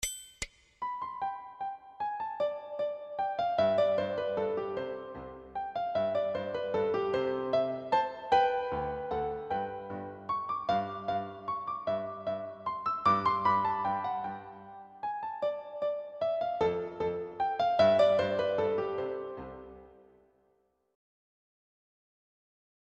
Without Pianist 1